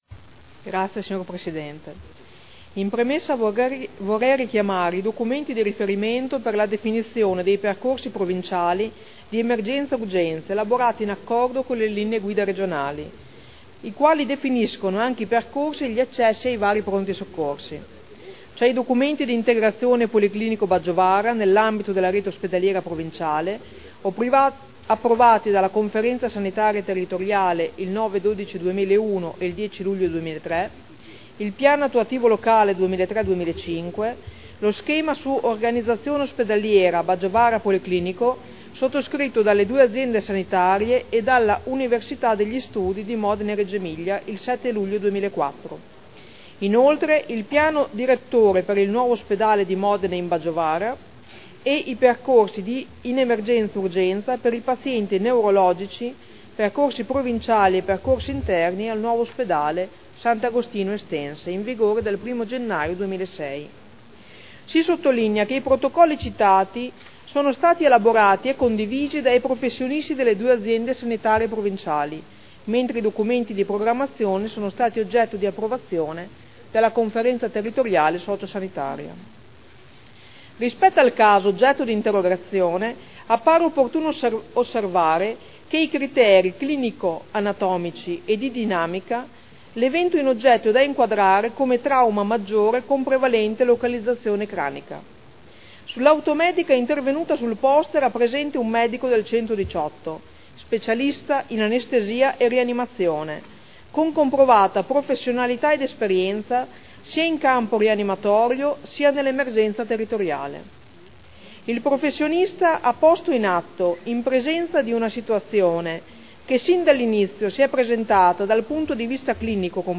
Francesca Maletti — Sito Audio Consiglio Comunale
Seduta del 05/12/2011.